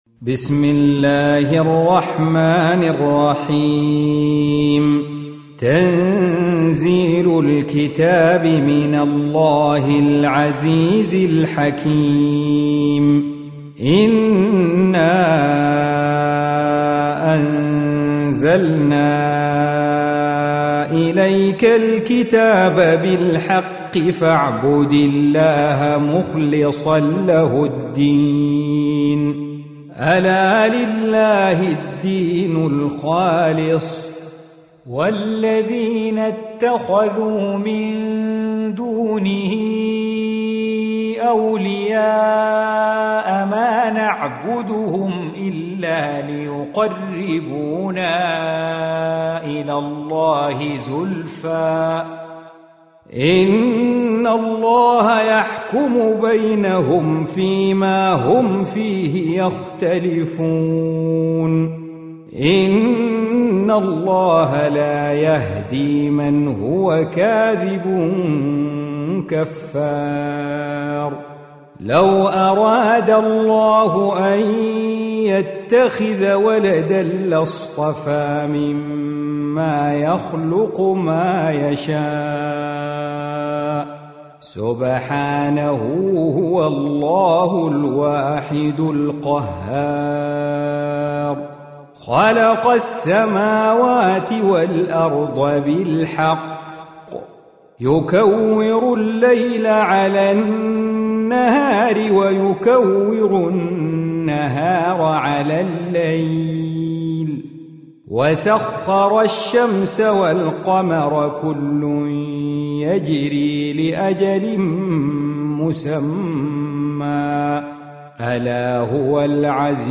39. Surah Az-Zumar سورة الزمر Audio Quran Tarteel Recitation
Surah Repeating تكرار السورة Download Surah حمّل السورة Reciting Murattalah Audio for 39. Surah Az-Zumar سورة الزمر N.B *Surah Includes Al-Basmalah Reciters Sequents تتابع التلاوات Reciters Repeats تكرار التلاوات